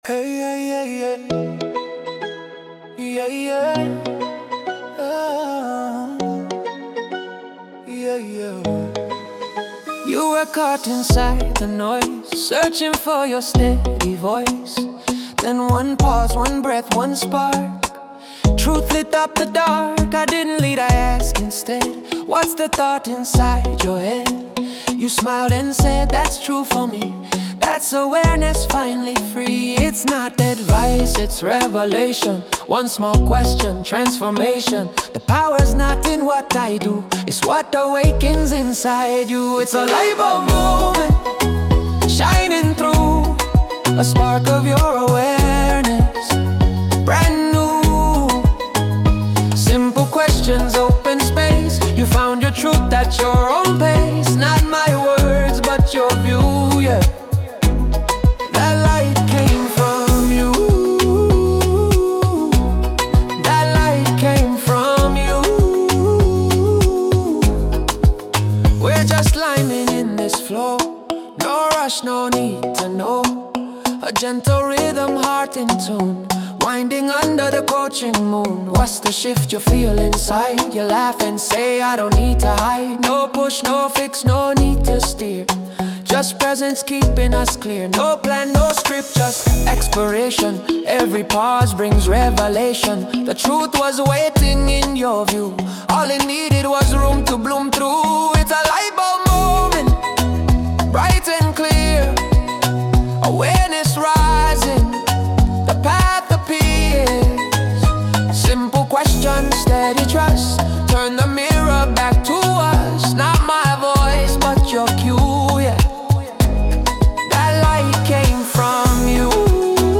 The Caribbean feel of this song